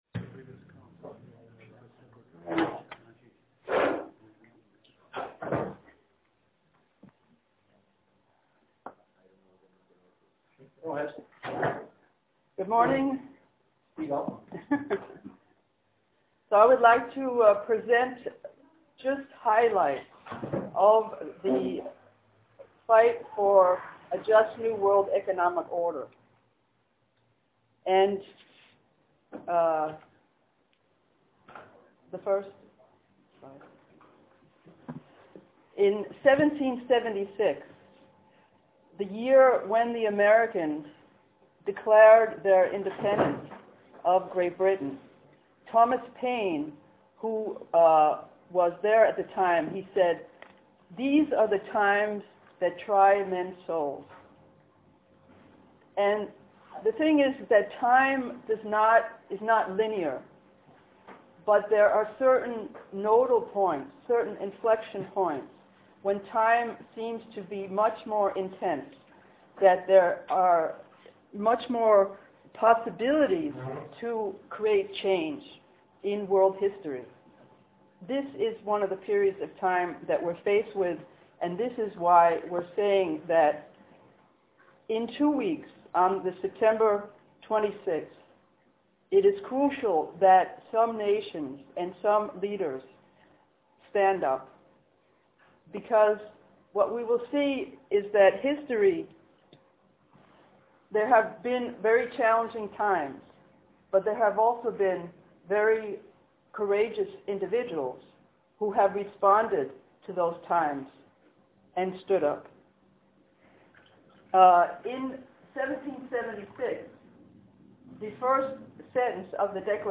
The Fight for a Just New World Economic Order MP3-sound file of presentation.